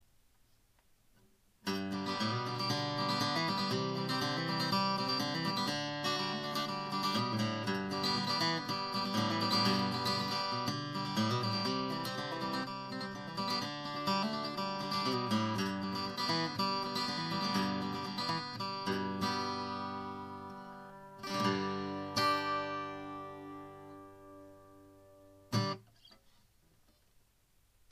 ICレコーダー任せのお手軽録音なので
■フラットピックでカントリー風
フラットピッキング　VG
どれも一発録り、、、。
ICレコーダーにステレオマイクをつないで
生音と比べると、やや中音域から高音域が